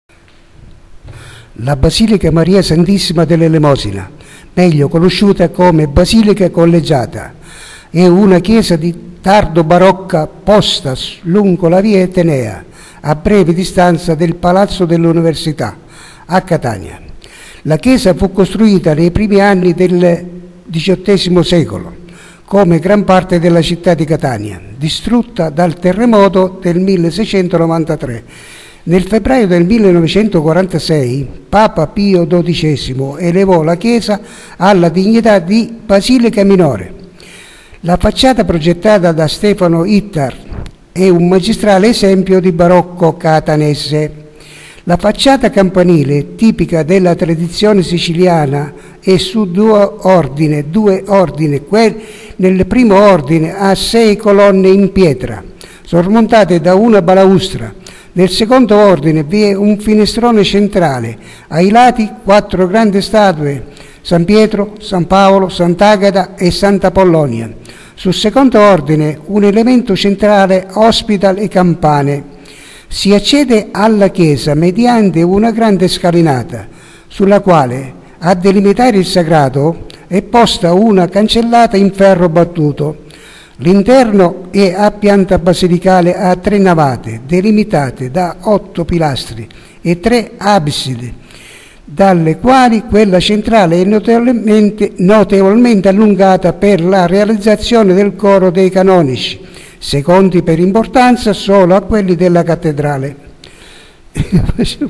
La voce di un nonno ci guida alla scoperta della Basilica collegiale di Catania.